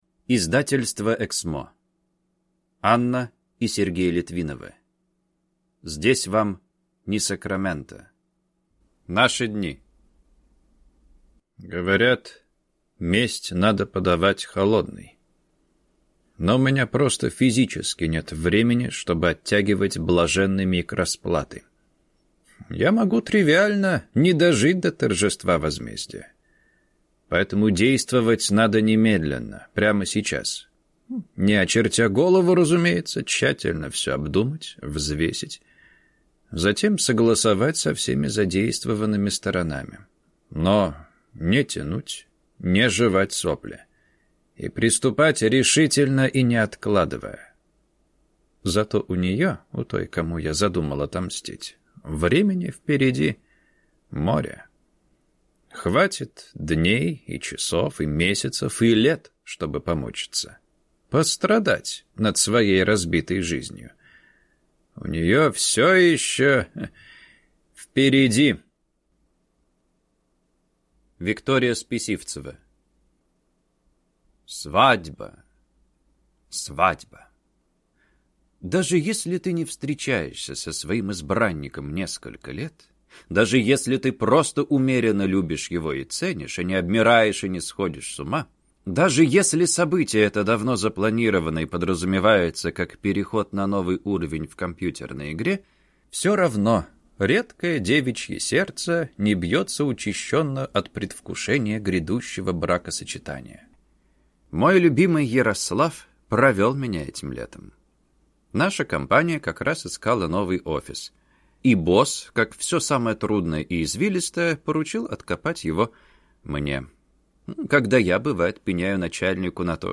Аудиокнига Космос в крови | Библиотека аудиокниг